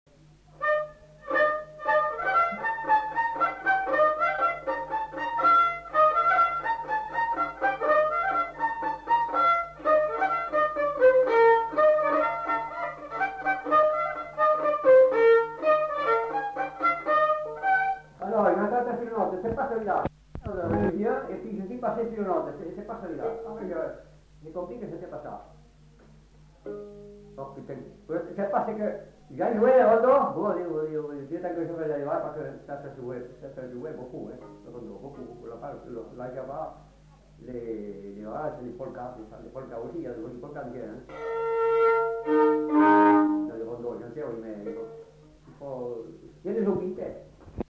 Aire culturelle : Lugues
Lieu : Casteljaloux
Genre : morceau instrumental
Instrument de musique : violon
Danse : rondeau